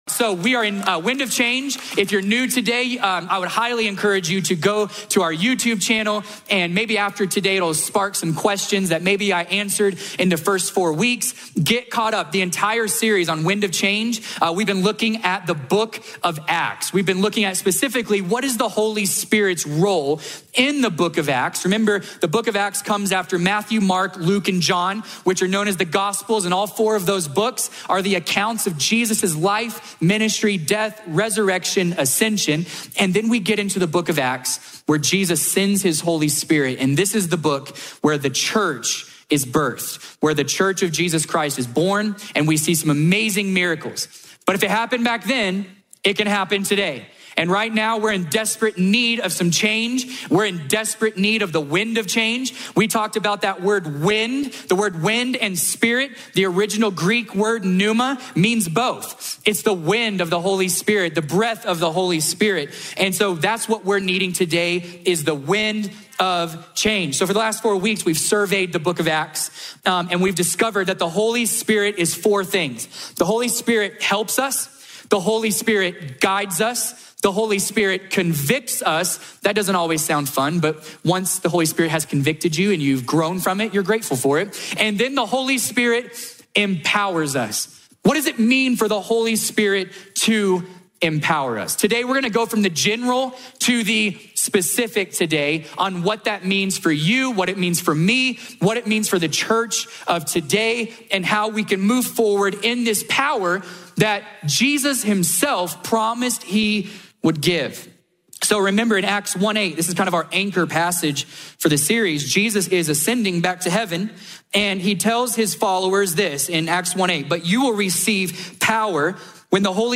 A message from the series "Wind of Change."